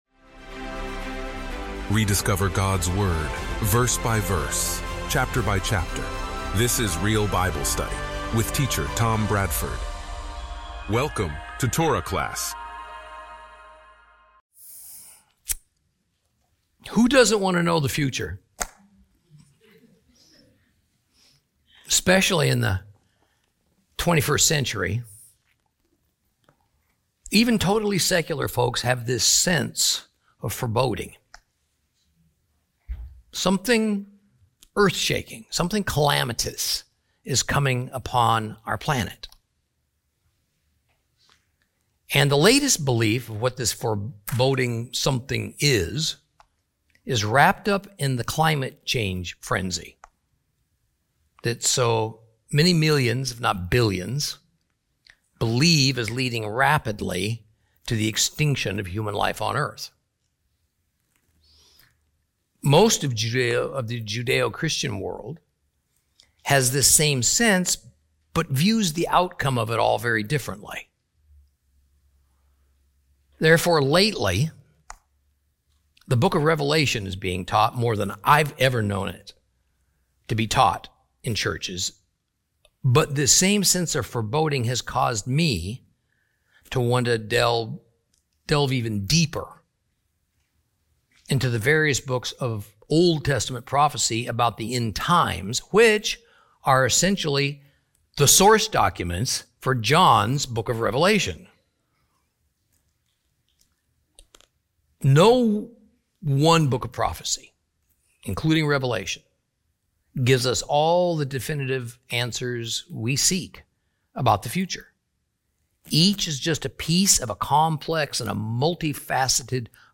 Teaching from the book of Joel, Lesson 4 Chapter 2.